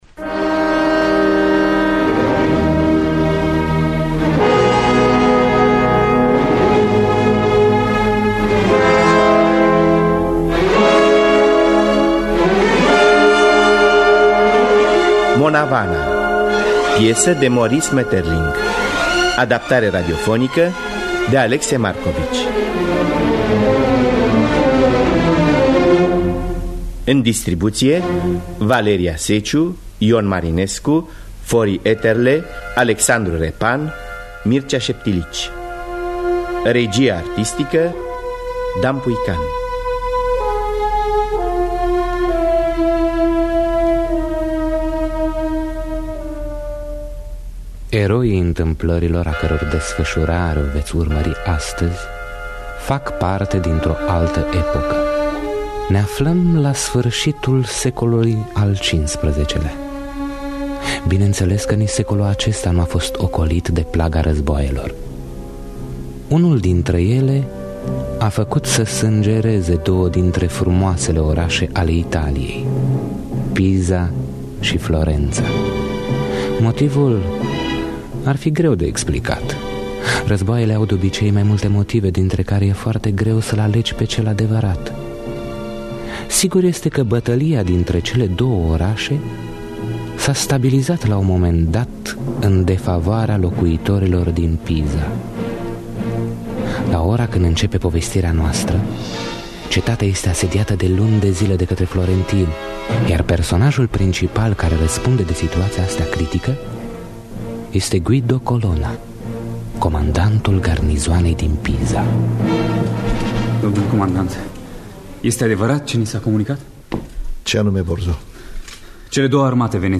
,,Monna Vanna” de Maurice Maeterlinck – Teatru Radiofonic Online